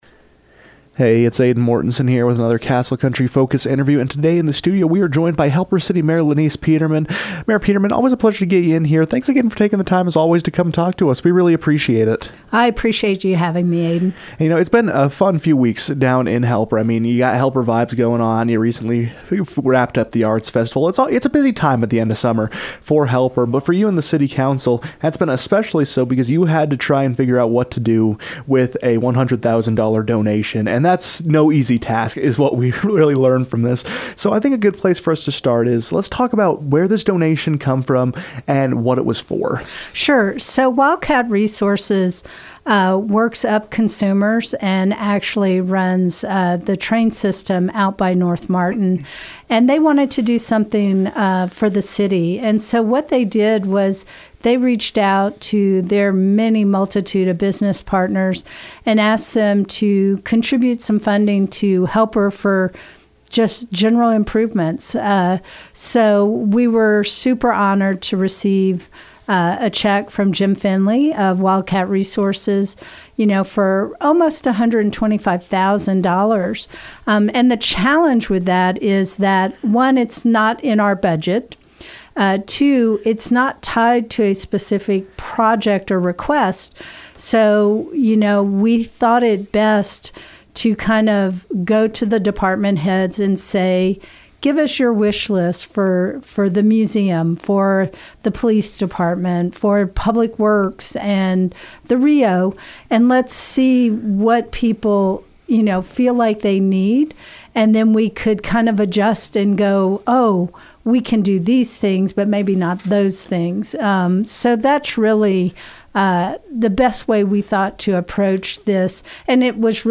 Following a meeting of the Helper City Council, which saw the body work to allocate a $121,000 donation, Helper City Mayor Lenise Peterman joined the KOAL newsroom to discuss the behind-the-scenes work of the council and how they kept the process transparent to the public.